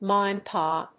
アクセントmá‐and‐pá